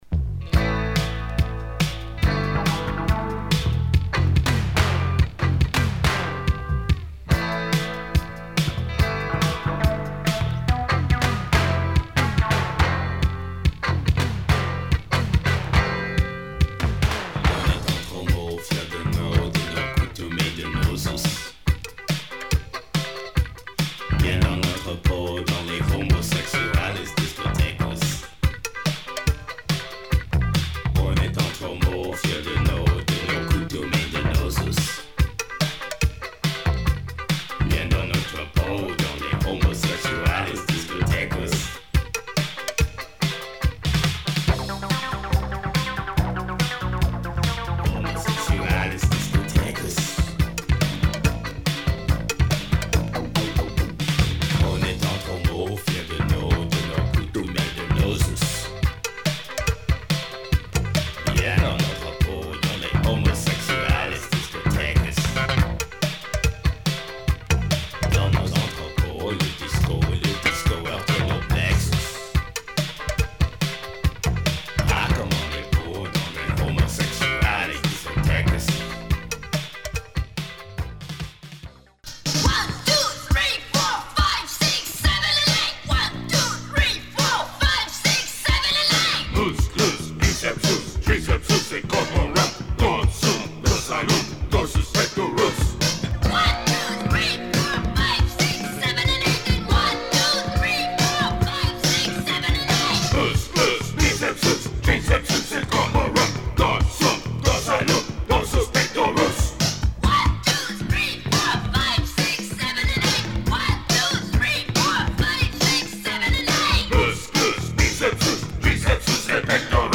disco anthem.
has some funky electro intentions
French reggae
bass